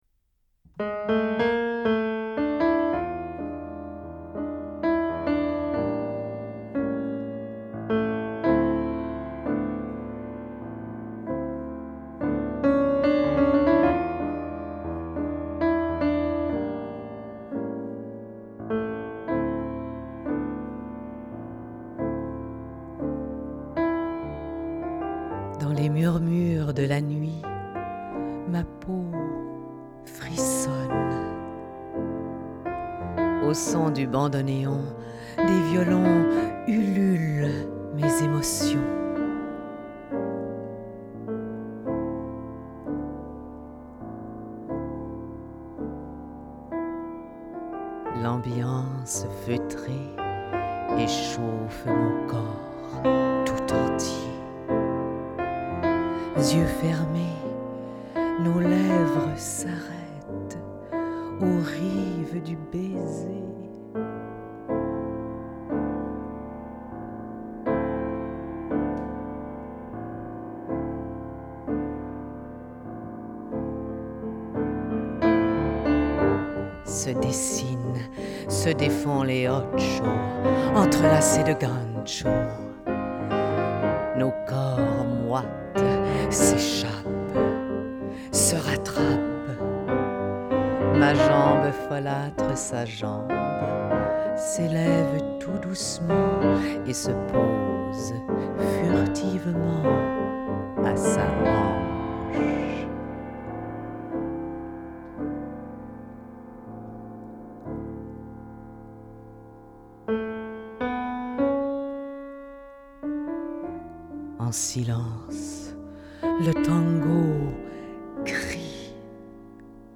Tango
Extrait de l'émission du Club de Minuit, déc. 2001
piano
Lecture du poème